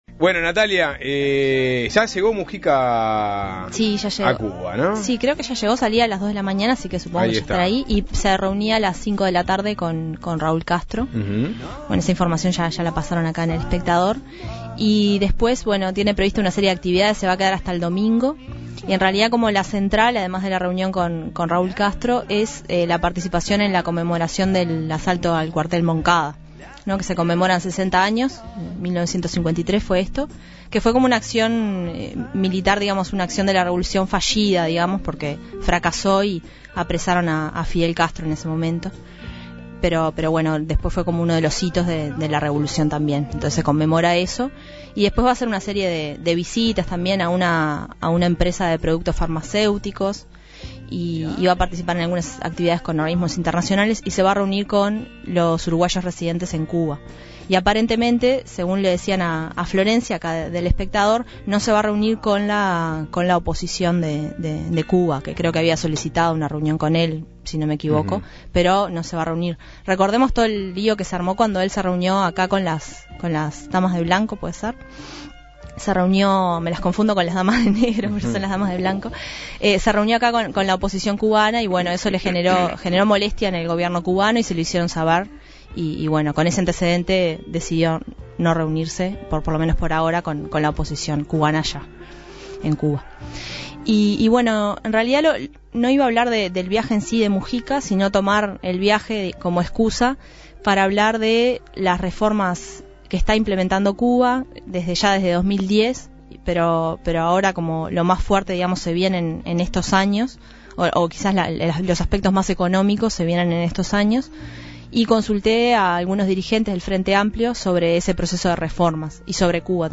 Opinaron el senador Eduardo Lorier del Partido Comunista, el diputado José Carlos Mahía de Asamblea Uruguay y el senador Roberto Conde del Partido Socialista.